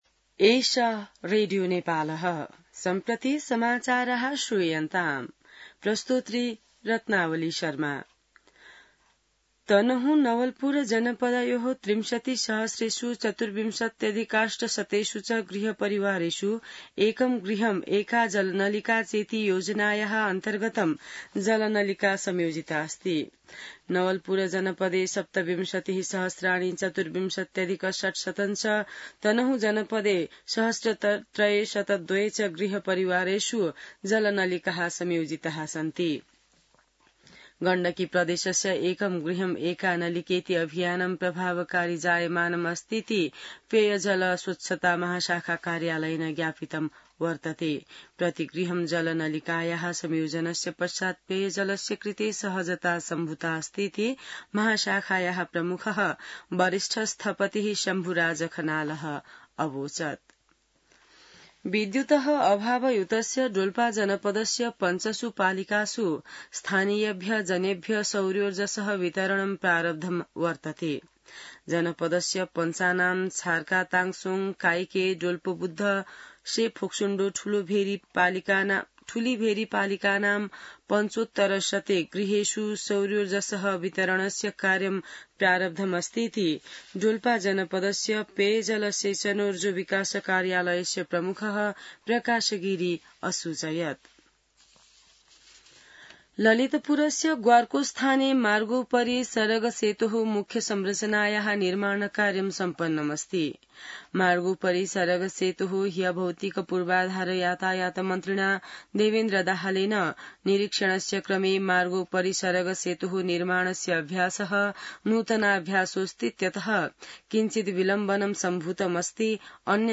An online outlet of Nepal's national radio broadcaster
संस्कृत समाचार : १ असार , २०८२